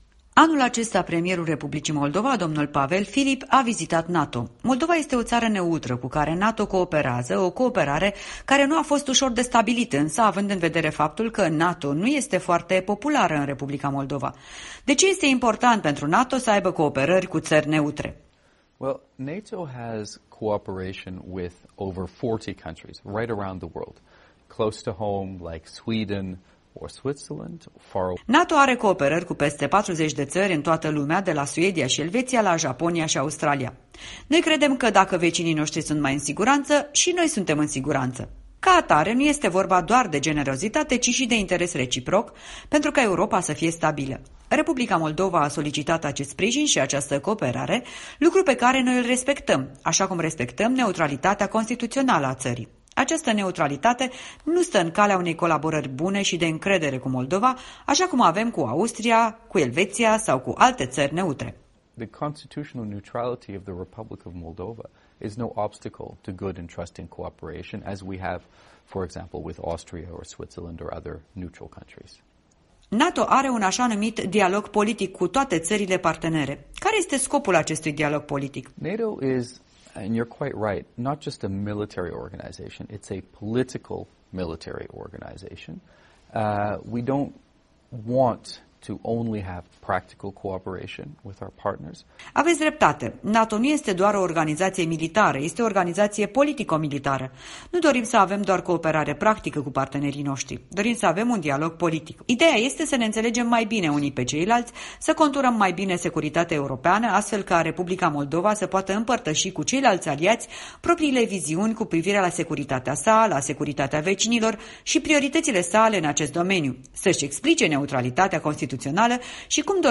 Un interviu cu James Appathurai, asistentul adjunct al secretarului general NATO pentru afaceri politice.